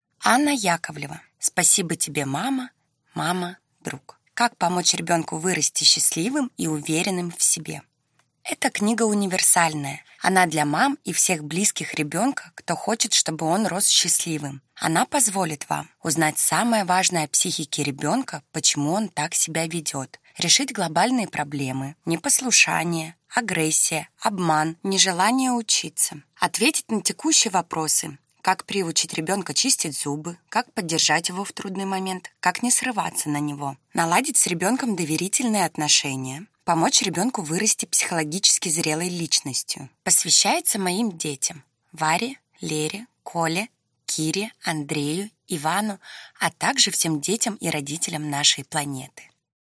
Аудиокнига Спасибо тебе, мама! Мама – друг | Библиотека аудиокниг